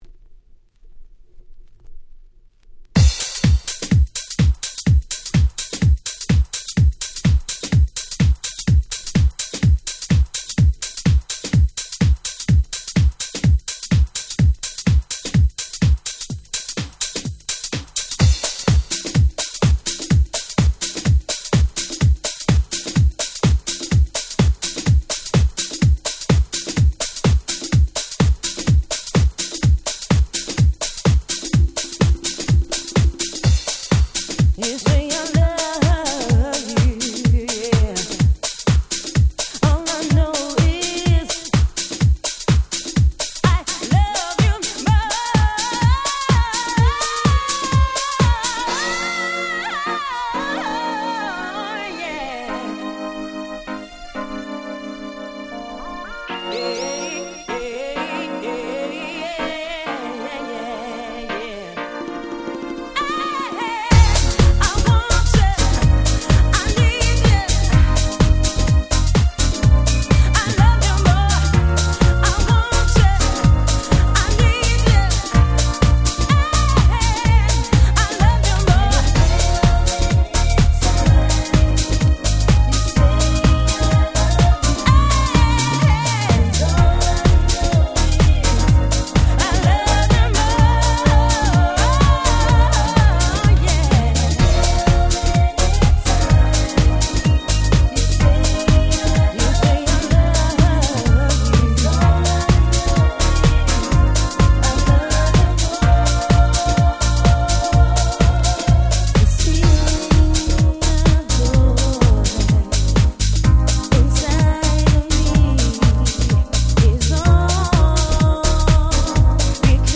99' Super Nice Vocal House !!
明け方やAfter Hoursにぴったりな美しすぎる女性ボーカルハウス。